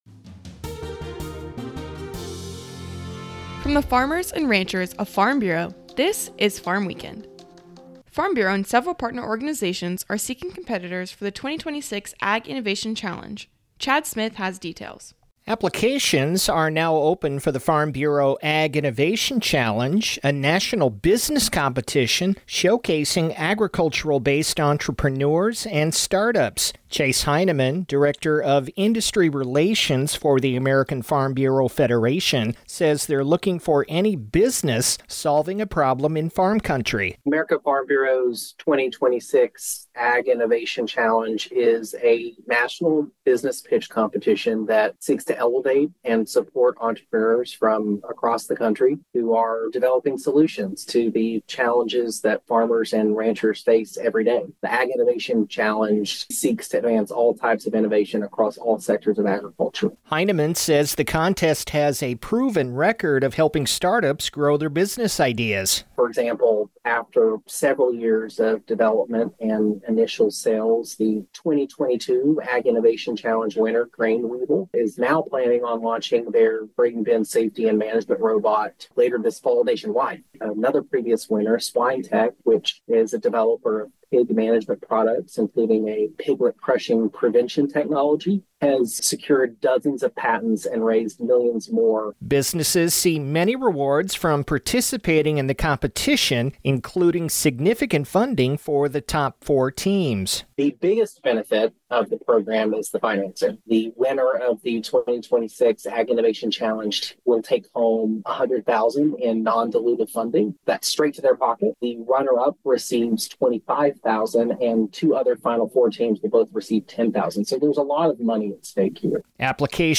A 5-minute radio program featuring a recap of the week's agriculture-related news and commentary.